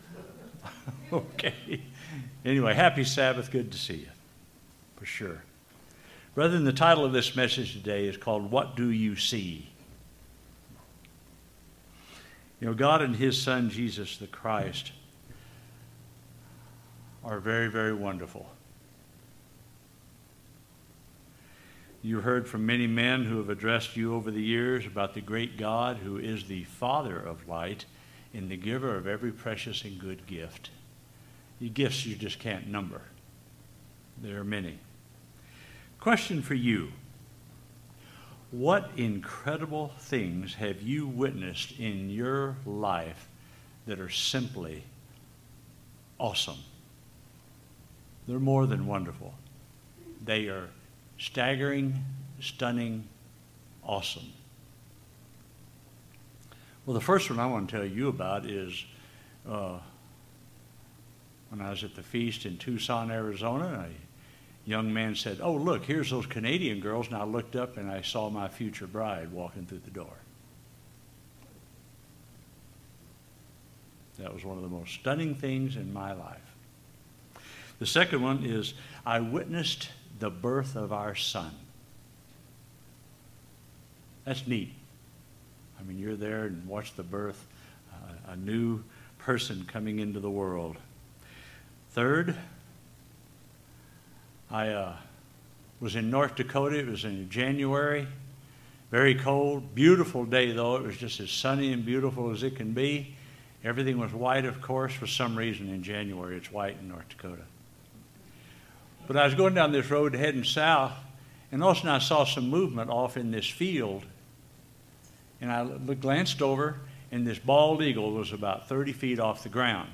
Sermons
Given in Oklahoma City, OK